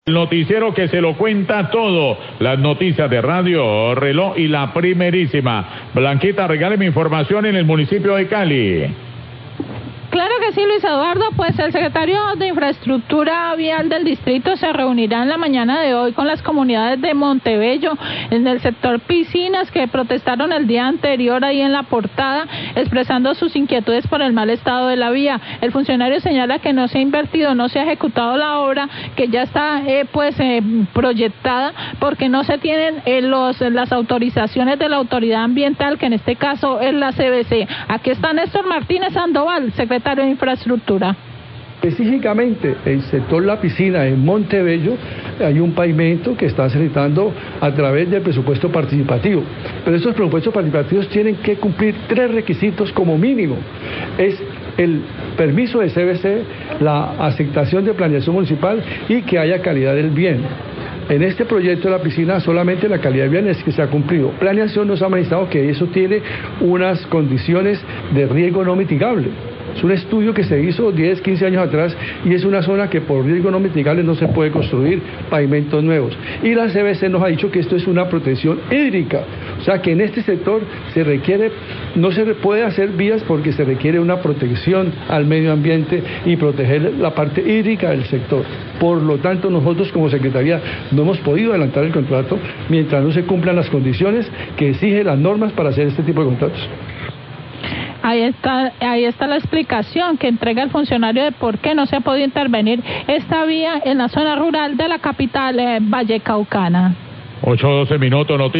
Secretario de infraestructura explicó las condiciones para poder realizar obras viales en sector de Montebello, Radio Reloj 810AM